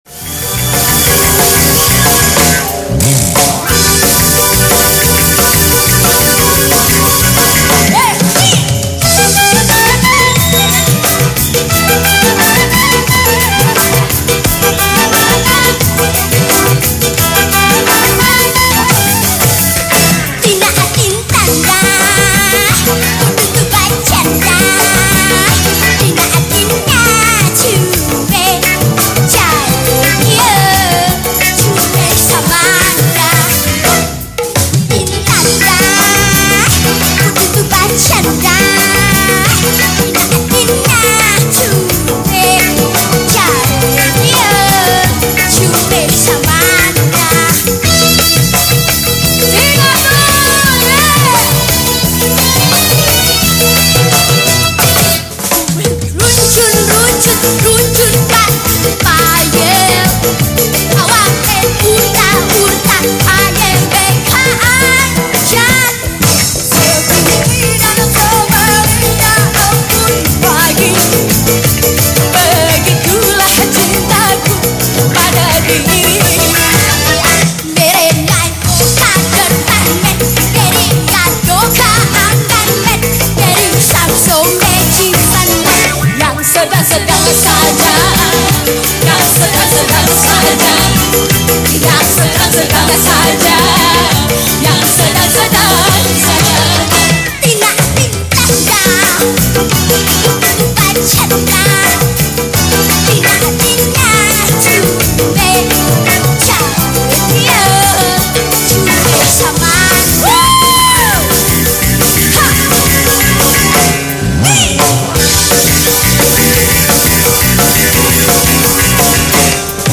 penyanyi pop